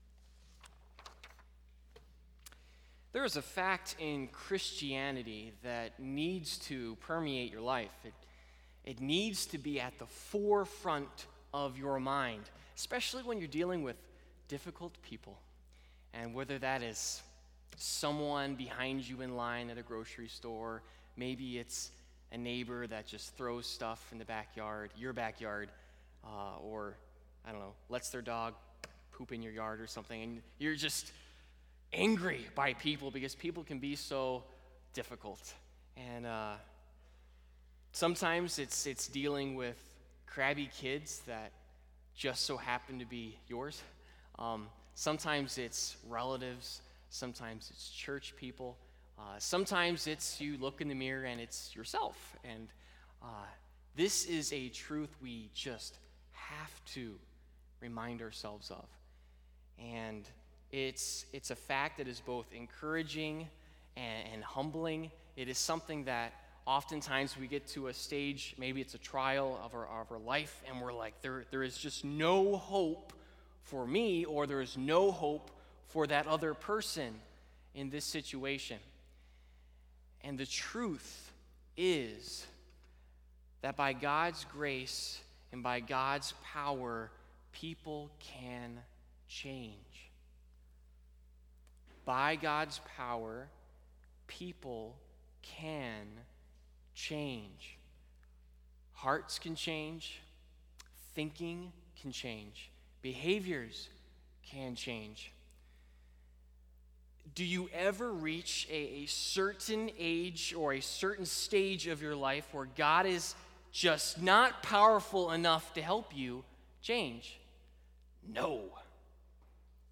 Topical Service Type: Sunday Morning Topics: Anger , Revenge , Sanctification The Preciseness of God »